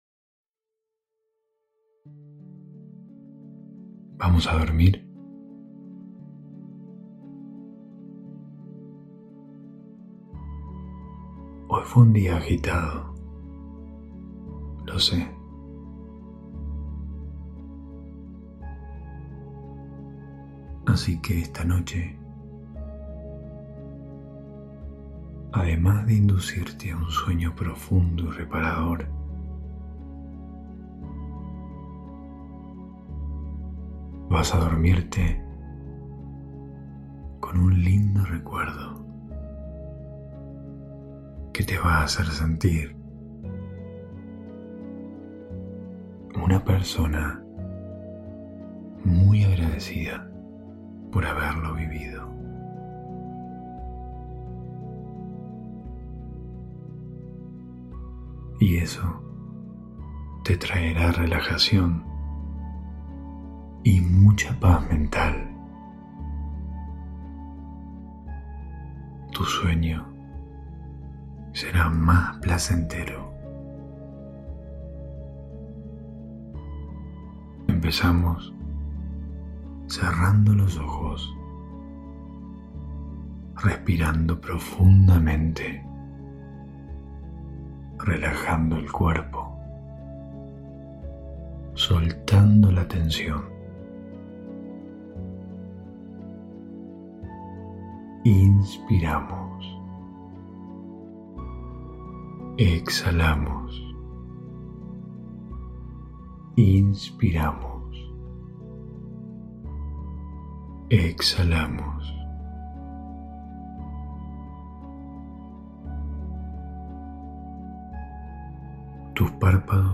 Hipnosis para dormir con un recuerdo lindo